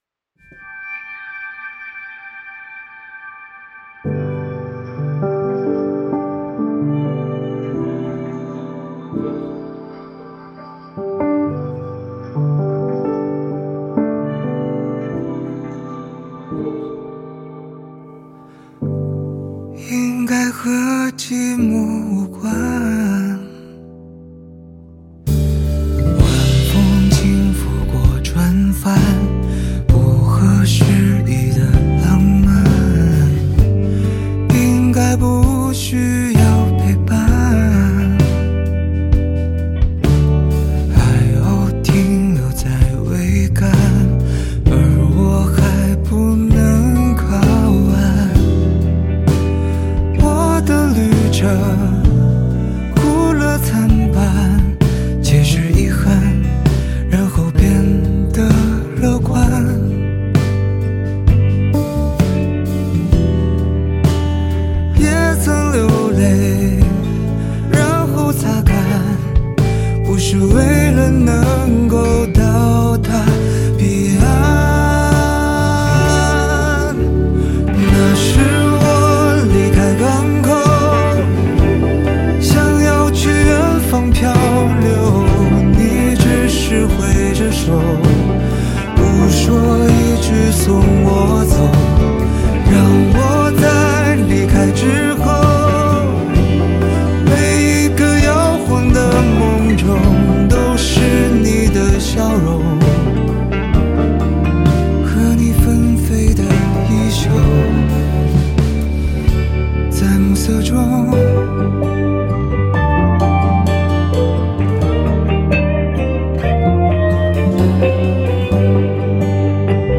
经典的情歌，优美的旋律，非常感谢楼主分享！